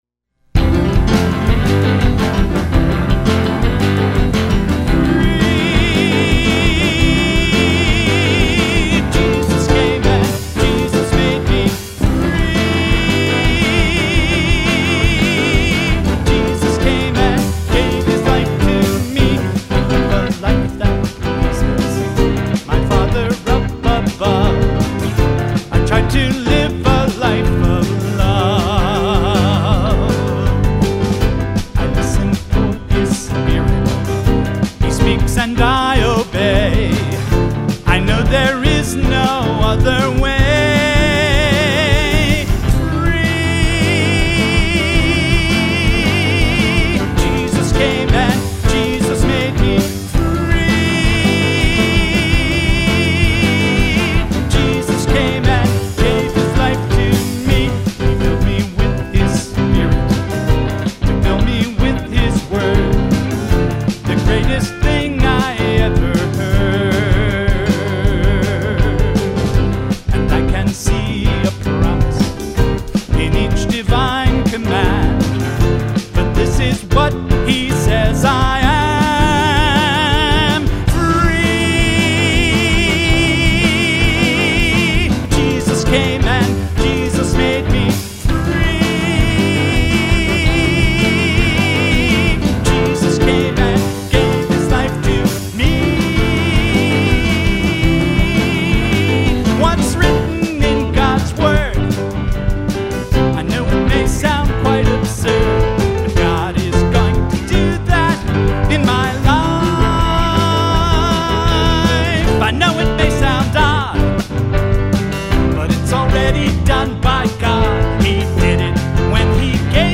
guitar
piano
drums